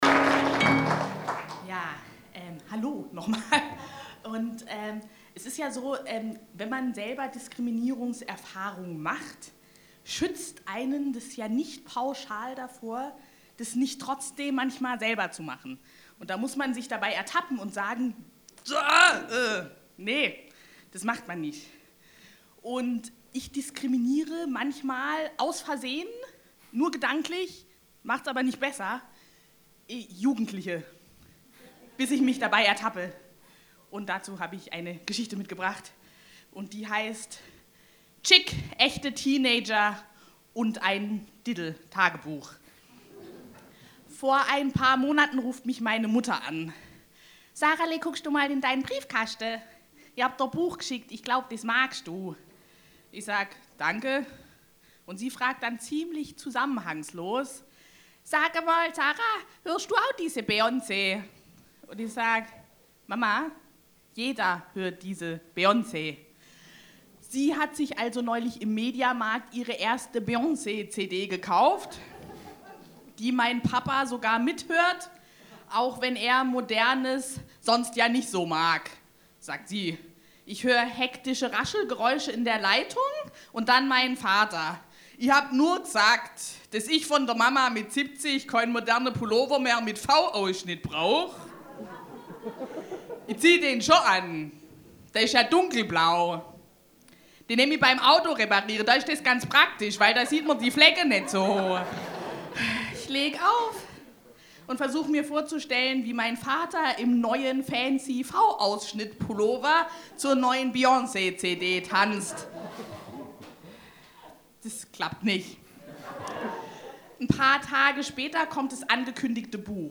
Events, Live-Übertragungen
Es wurde musiziert, Geschichten vorgelesen und Gedichte vorgetragen.
BENEFIZ-COMEDY-MIXED-SHOW